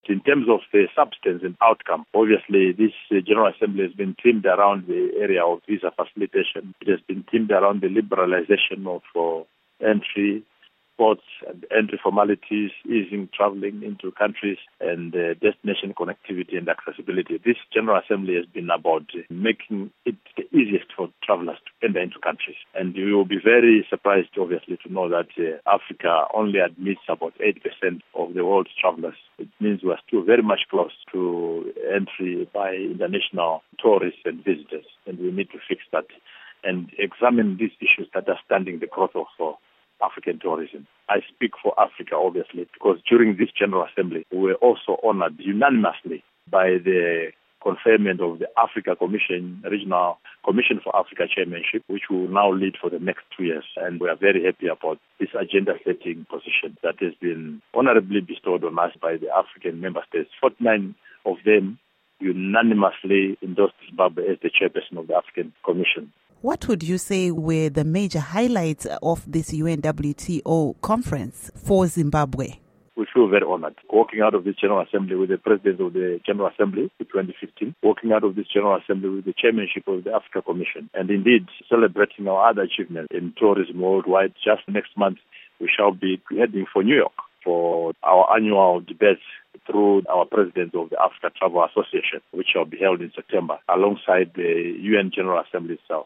Interview Wit Walter Mzembi